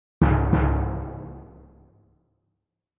Ниже звуки ударов литавры с разной частотой и силой, которые вы можете послушать онлайн и загрузить на телефон, планшет или компьютер бесплатно.